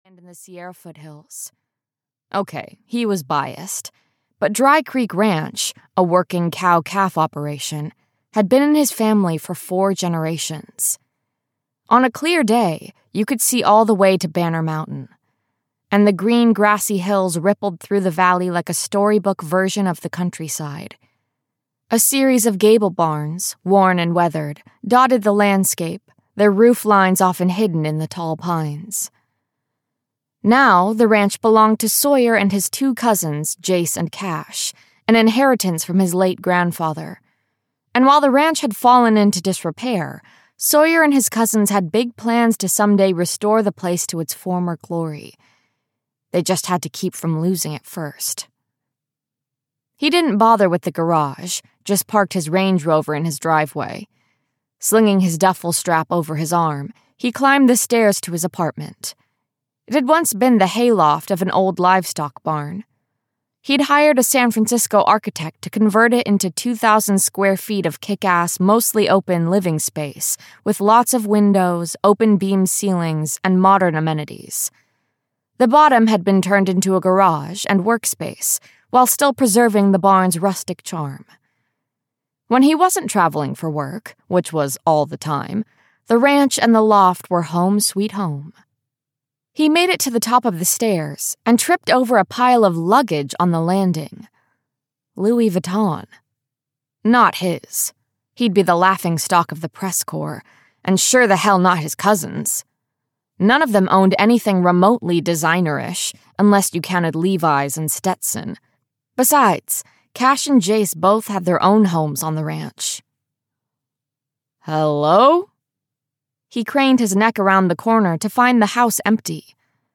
Cowboy Strong (EN) audiokniha
Ukázka z knihy